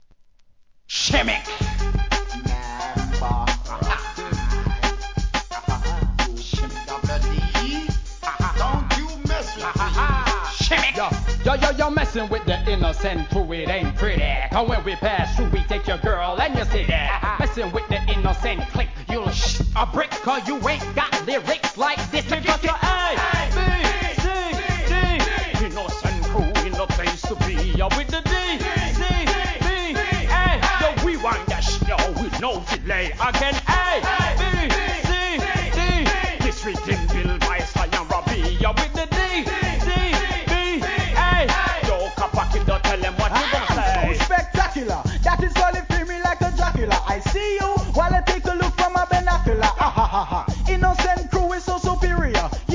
REGGAE
'90s JAMAICAN RAP!!